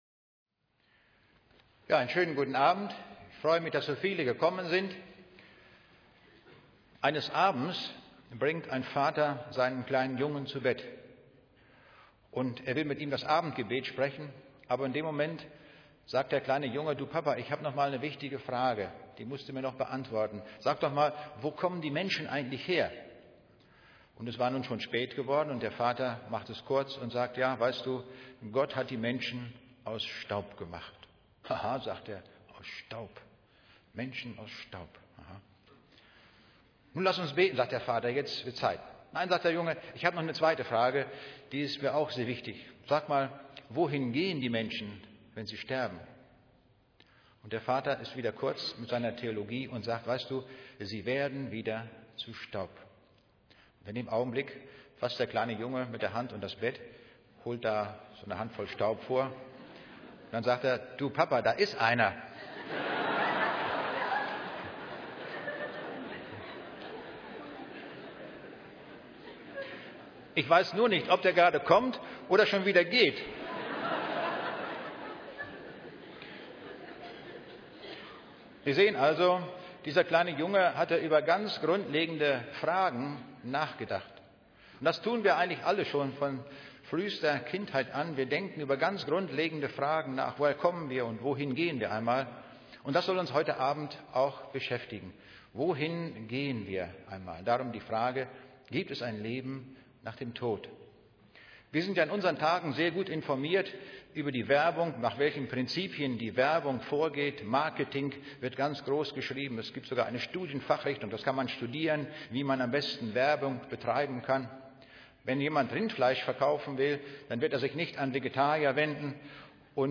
Audiovortraege